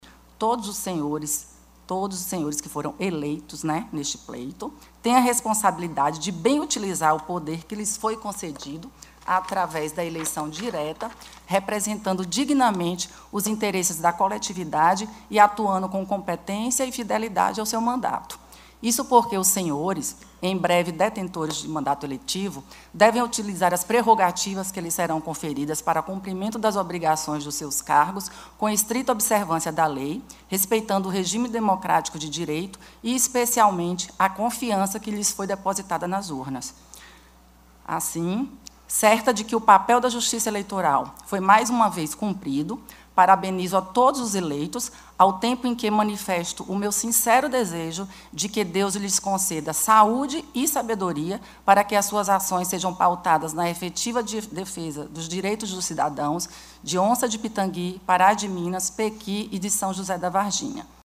A diplomação aconteceu no plenário da Câmara Municipal foi aberta com a fala da juíza eleitoral que expressou seu desejo por um futuro mandato com destaque para o benefício do povo, que elegeu seus representantes agora diplomados.